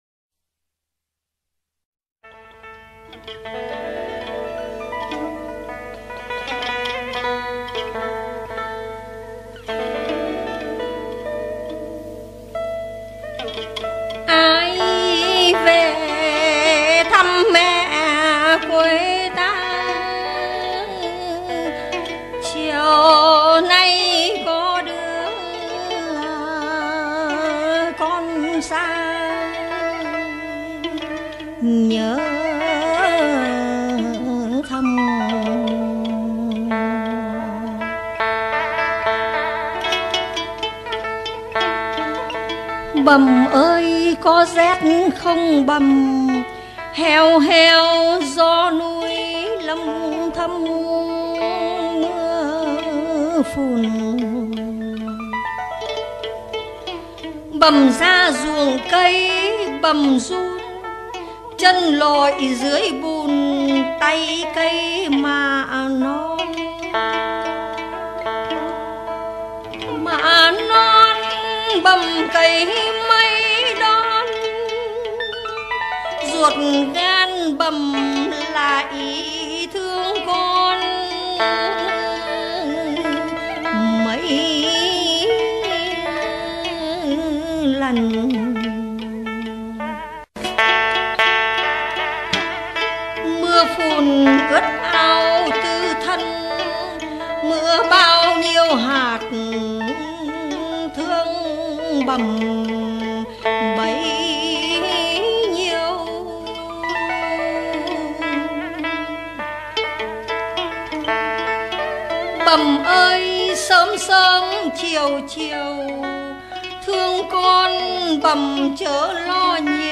(5)Ngâm thơ- Bầm ơi - Tố Hữu - NSƯT Trần Thị Tuyết.mp3